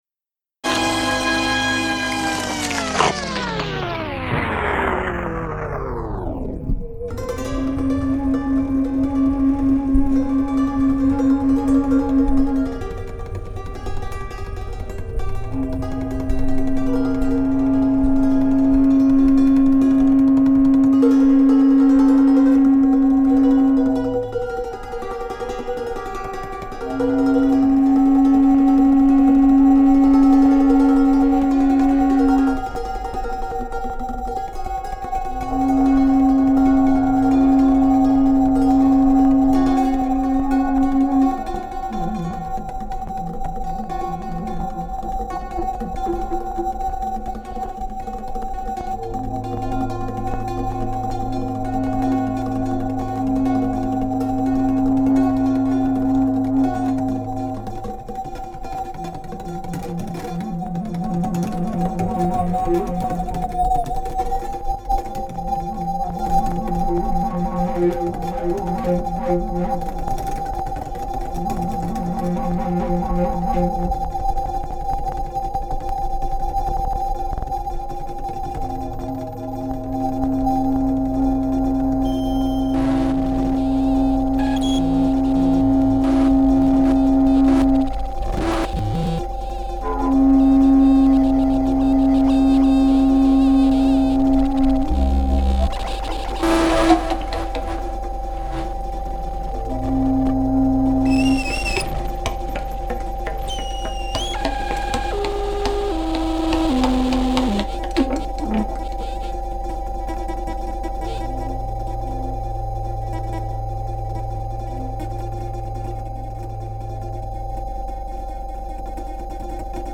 electronics
extended piano & clavichord